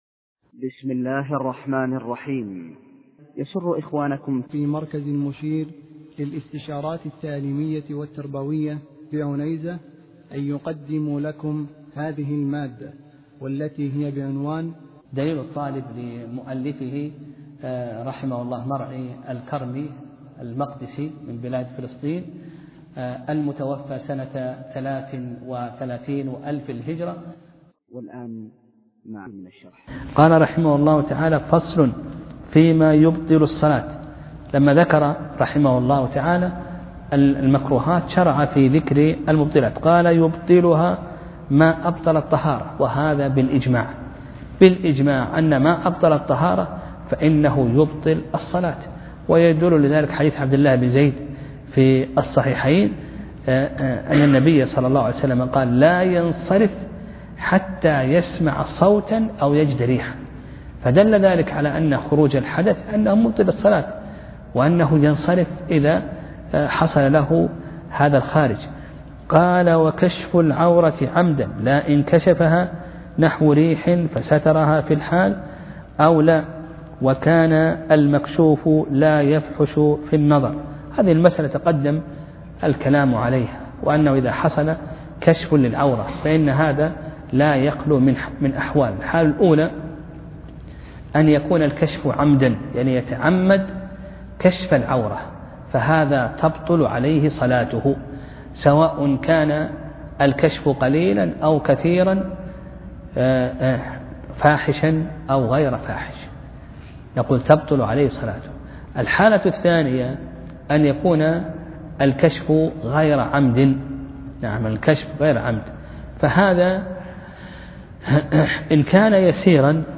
درس (35) : كتاب الصلاة : فصل فيما يبطل الصلاة